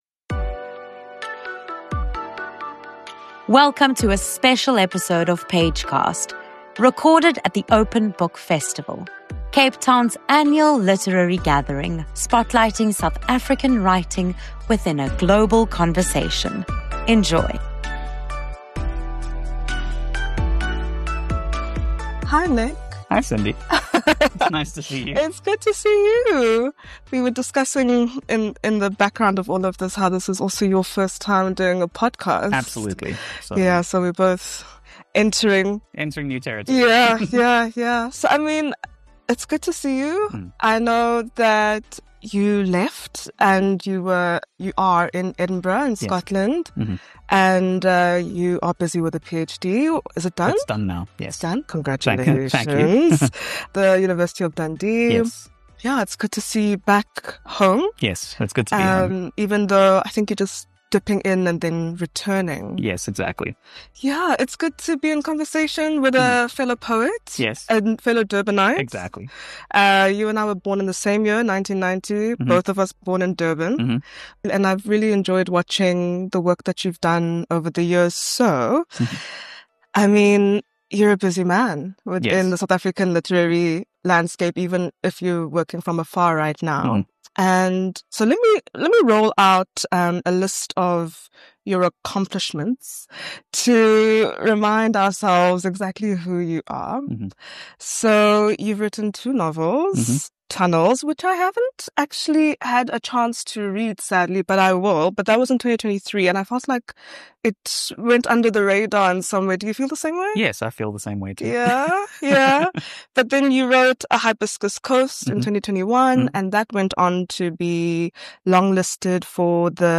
Pagecast was at the 2025 Open Book Festival, where we spoke with authors about their writing journeys and the stories they share with readers.